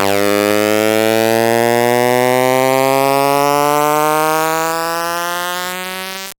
VEC3 Alarm FX
VEC3 FX Alarm 09.wav